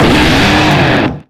Cries
EMBOAR.ogg